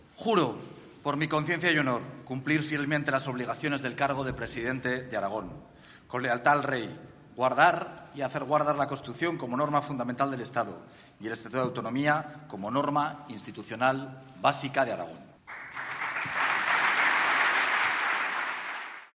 Momento en el que Azcón ha jurado su cargo como nuevo presidente aragonés.